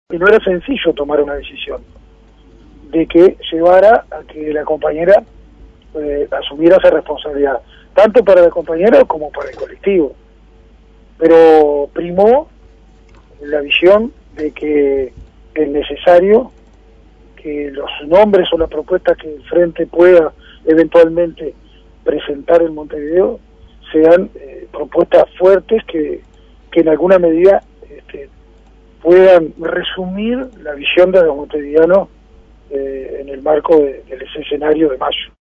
El diputado Aníbal Pereyra, integrante del Ejecutivo del MPP, explicó por qué la confirmación de Topolansky se demoró.